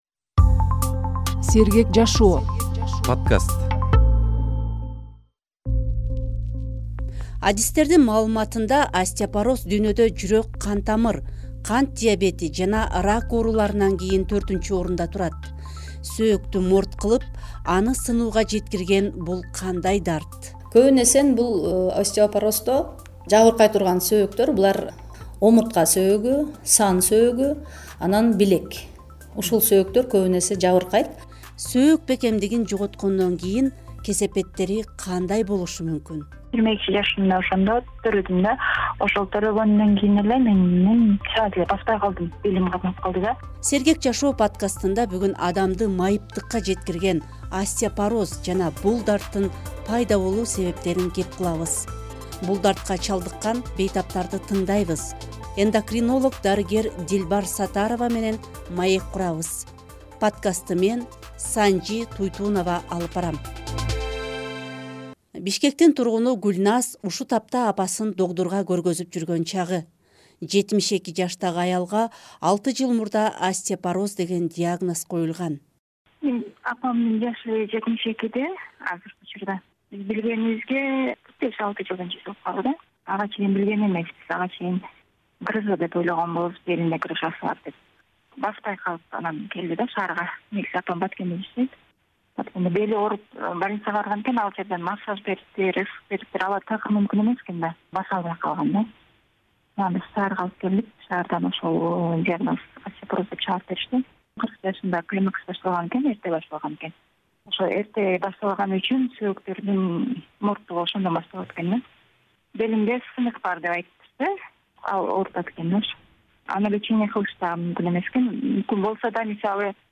“Сергек жашоо” подкастында бүгүн адамды майыптыкка жеткирген остеопороз жана бул дарттын пайда болуу себептерин кеп кылабыз. Бул дартка чалдыккан бейтаптарды тыңдайбыз.